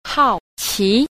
9. 好奇 – hàoqí – hảo kỳ (hiếu kỳ)